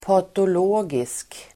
Ladda ner uttalet
Folkets service: patologisk patologisk adjektiv, pathological Uttal: [patol'å:gisk] Böjningar: patologiskt, patologiska Synonymer: sjuklig Definition: sjuklig, abnorm pathological adjektiv, patologisk
patologisk.mp3